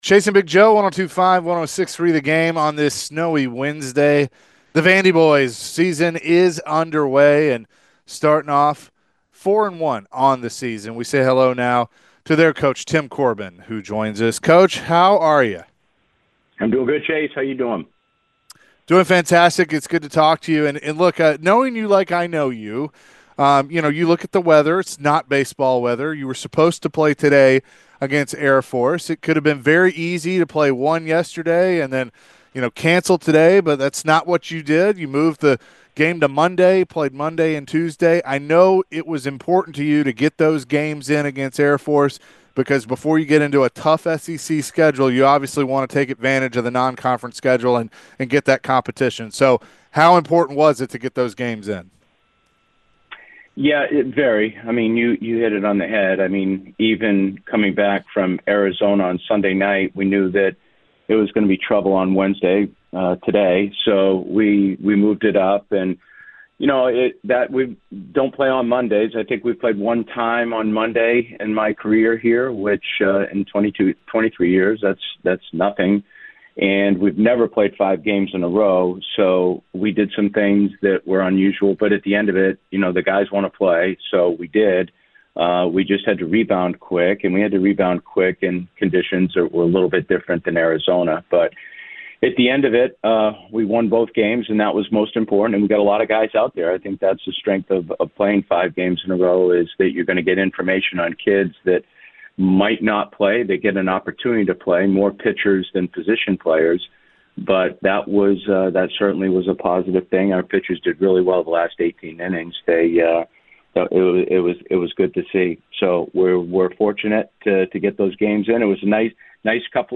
Vanderbilt Commodores Coach Tim Corbin joined the show and shared his excitement for the beginning of the baseball season and what to expect this season from the VandyBoys.